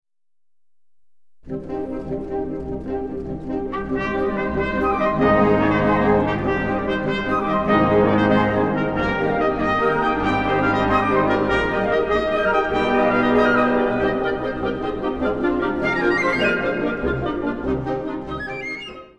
Catégorie Harmonie/Fanfare/Brass-band
Sous-catégorie Musique de concert, arrangement
Instrumentation Ha (orchestre d'harmonie)